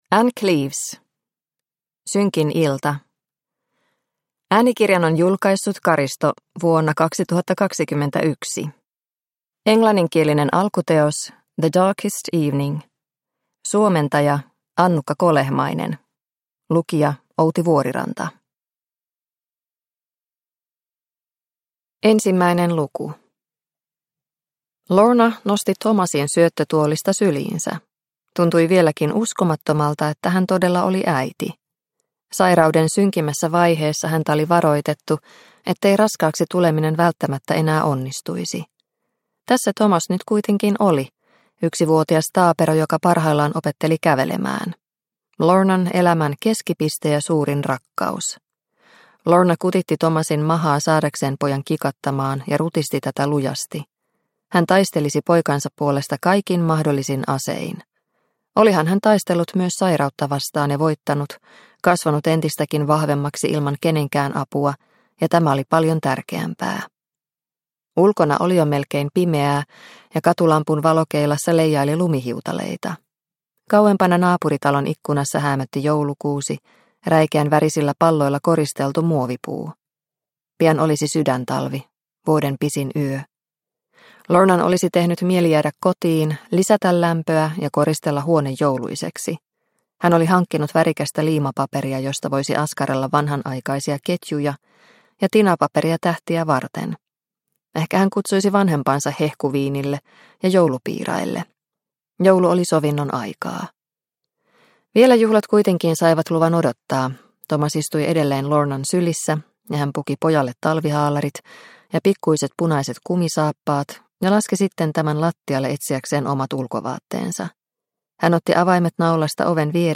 Synkin ilta – Ljudbok – Laddas ner